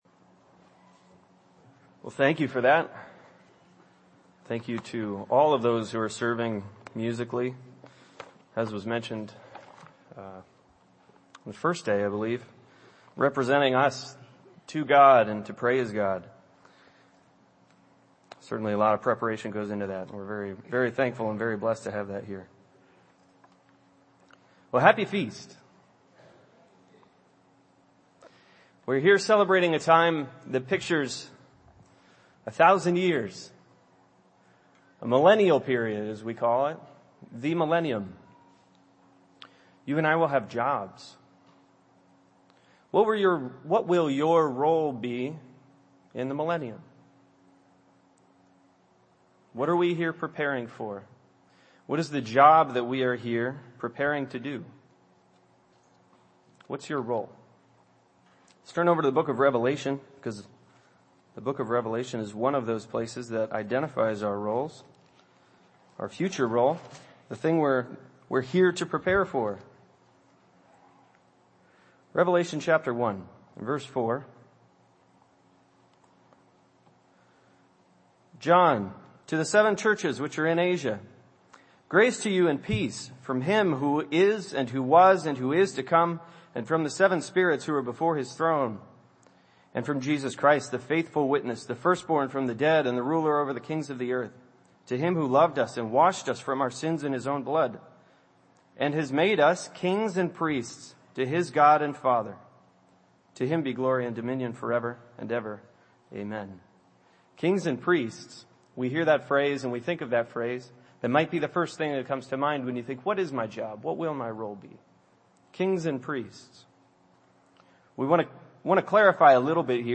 This sermon was given at the Wisconsin Dells, Wisconsin 2015 Feast site.